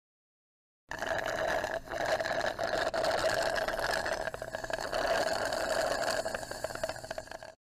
دانلود آهنگ نوشیدن با نی 2 از افکت صوتی انسان و موجودات زنده
دانلود صدای نوشیدن با نی 2 از ساعد نیوز با لینک مستقیم و کیفیت بالا
جلوه های صوتی